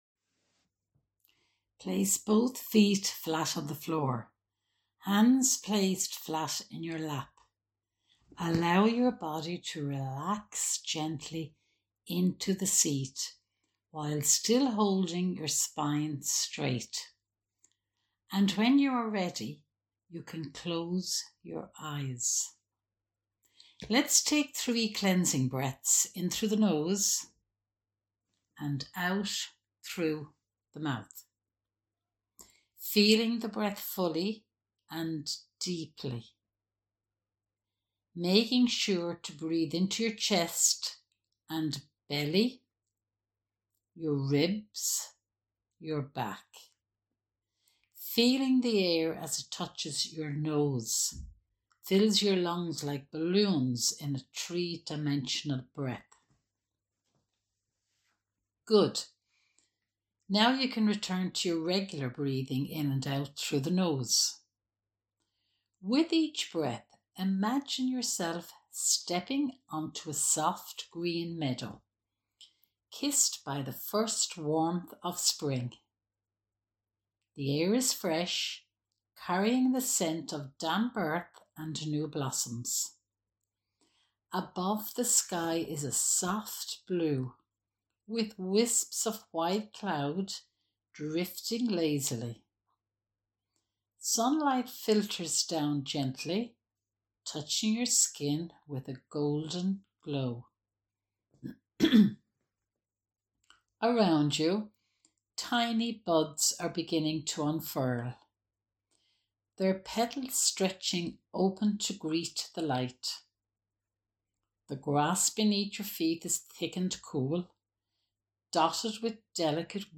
Ostara Meditation
WY02-meditation-ostara.mp3